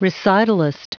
Prononciation du mot recitalist en anglais (fichier audio)
Prononciation du mot : recitalist